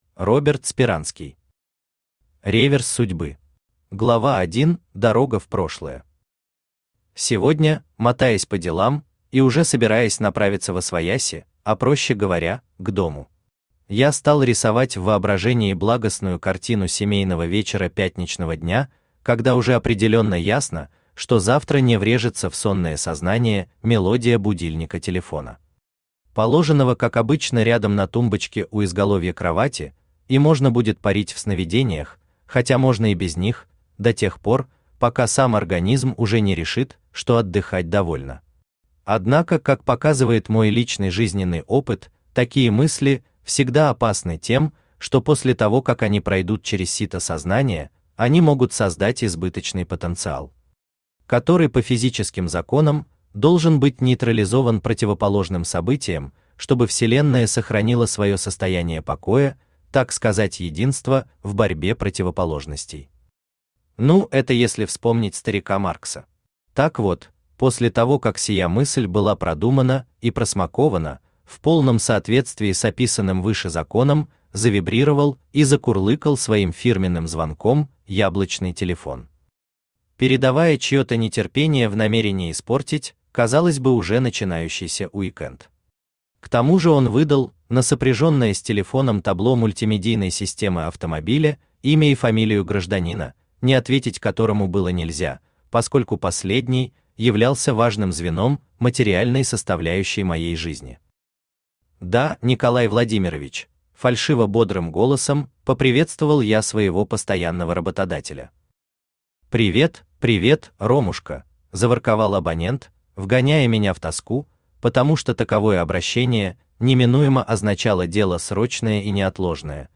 Aудиокнига Реверс судьбы Автор Роберт Юрьевич Сперанский Читает аудиокнигу Авточтец ЛитРес.